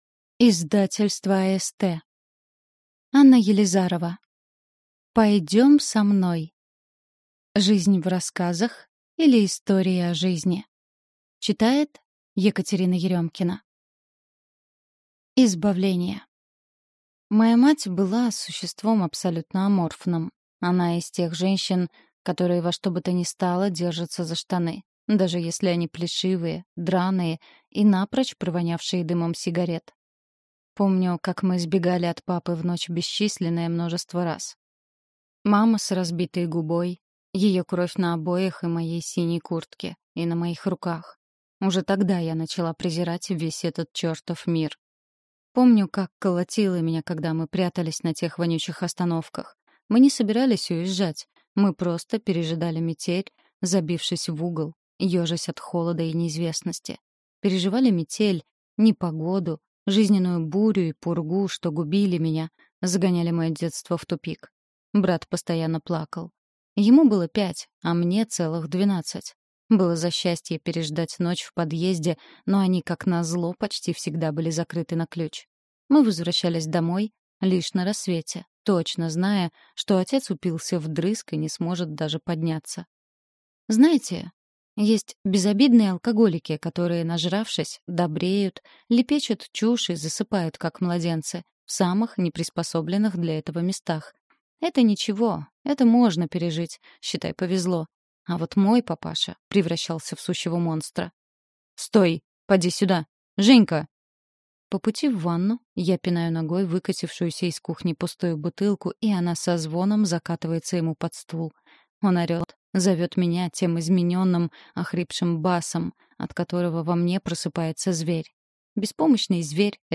Аудиокнига Пойдем со мной. Жизнь в рассказах, или Истории о жизни | Библиотека аудиокниг